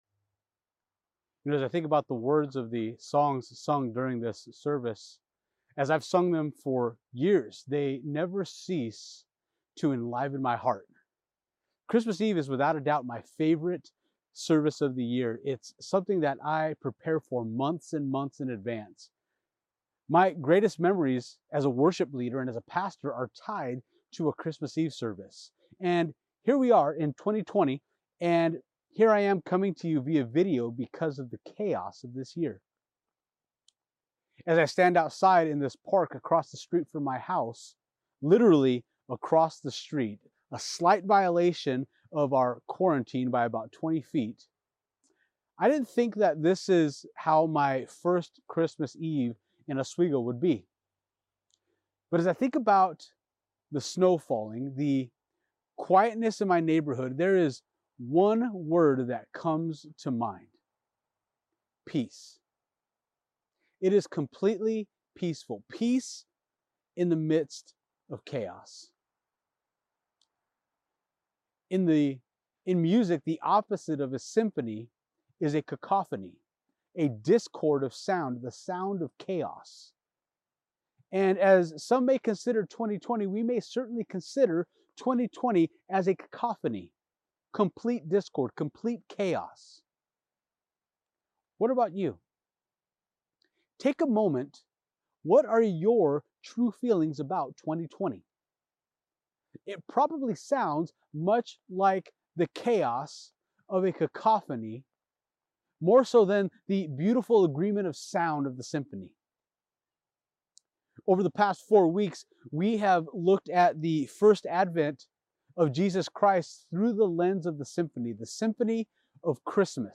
Christmas-Eve-Sermon-Final.mp3